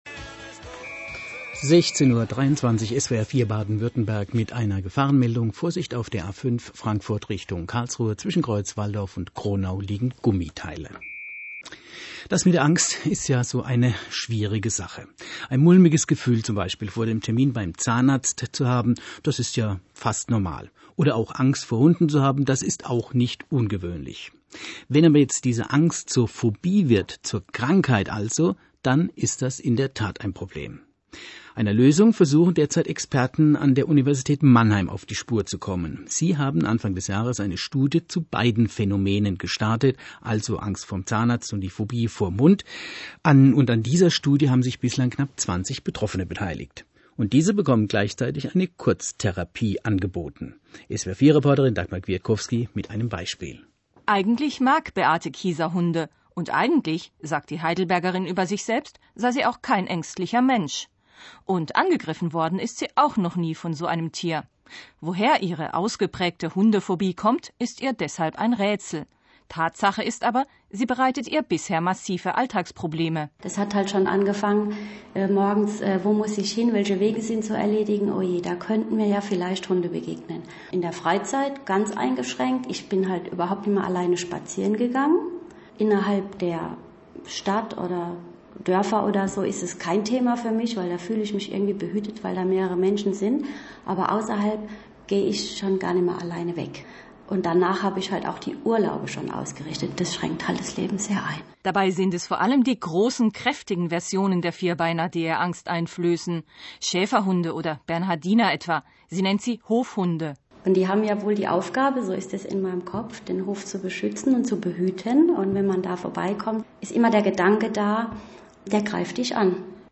SWR4 – berichtet über die Forschung zu Hundephobien des Lehrstuhls für Klinische und Biologische Psychologie